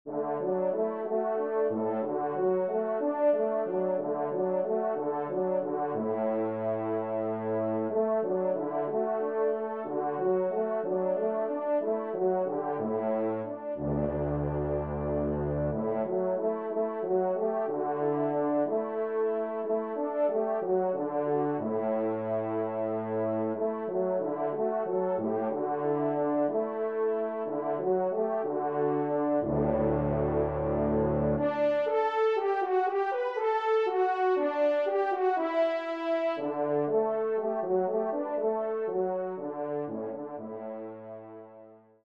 Pupitre Basse (en exergue)